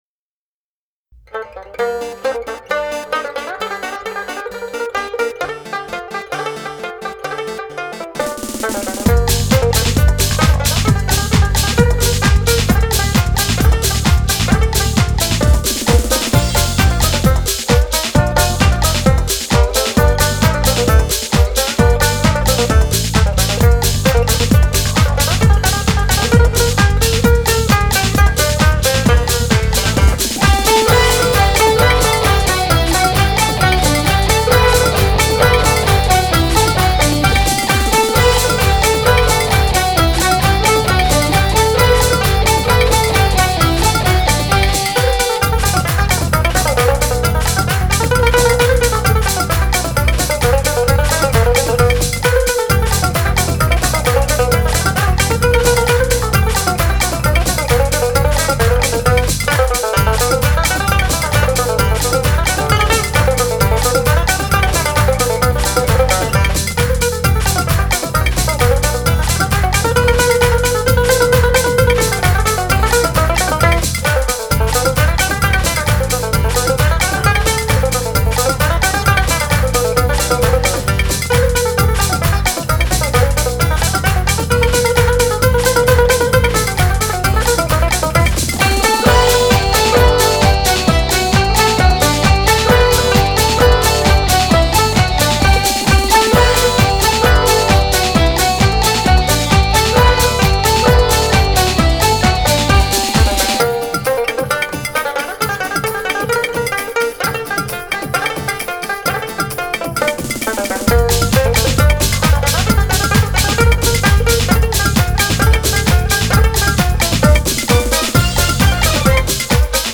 0016-三弦名曲土耳其进行曲.mp3